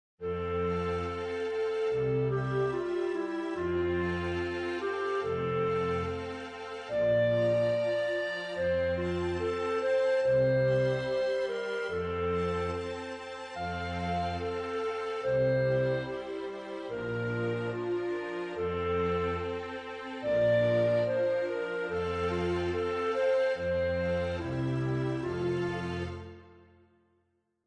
• la viola esegue, in figurazioni di minime, la quinta dell'accordo;
• i violini II procedono omoritmicamente ad intervalli di sesta inferiore con i violini I.